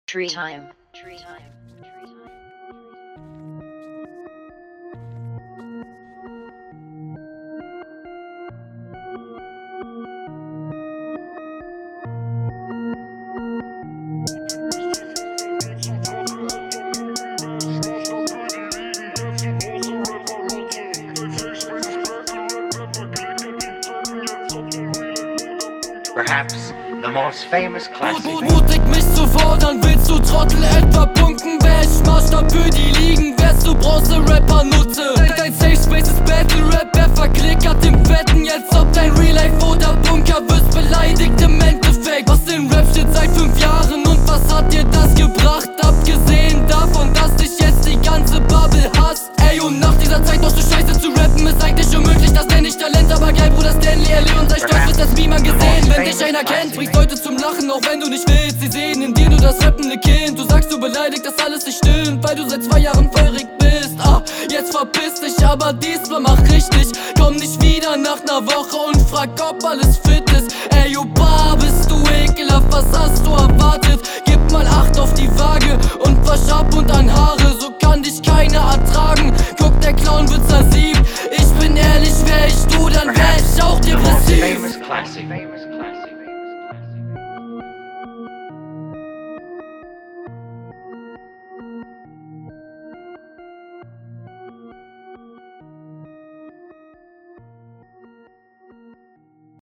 Delivery kommt sehr brutal. Reime sind gut gesetzt und werden auch schön druckvoll betont, Finde …